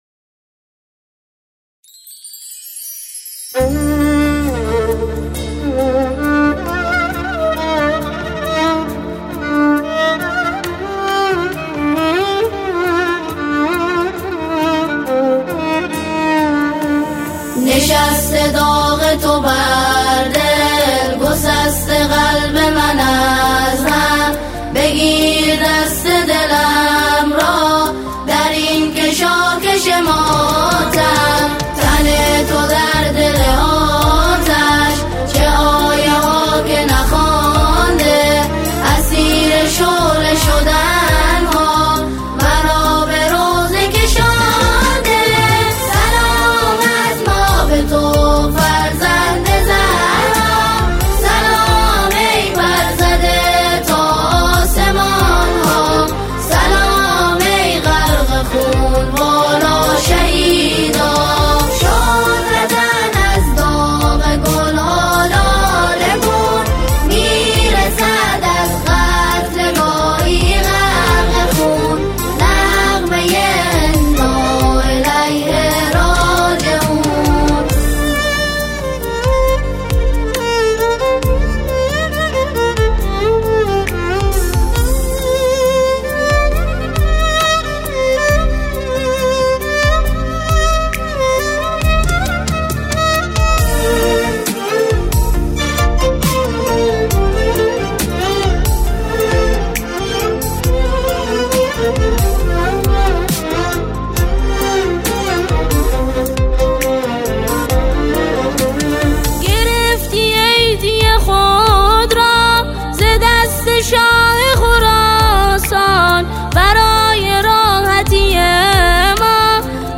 سرود شهید رئیسی